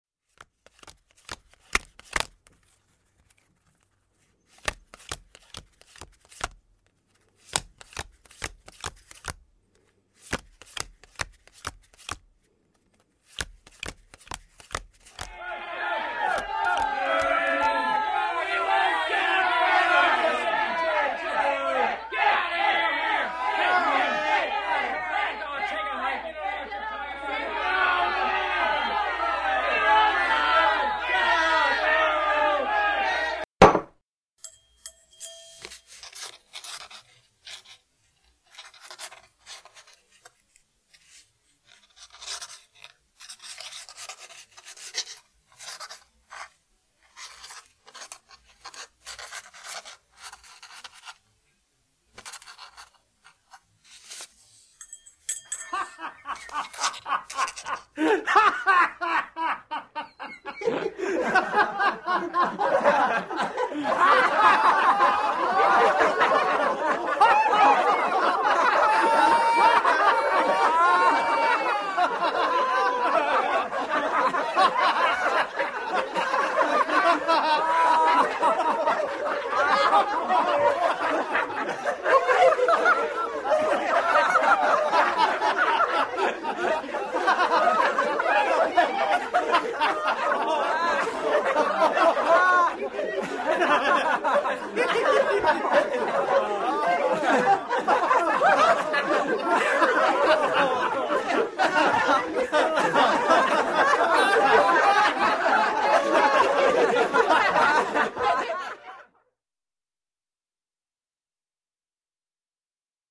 (Звукові доріжки «Стан запорожців ввечері», «Приготування вечері», «Пишемо лист і сміємося»; предмети, що є джерелами запахів – диму, сіна, їжі і напоїв; предмети: одяг козаків і сільських жителів 17 століття, домбра, гральні карти, перо і чорнильниця, свічник).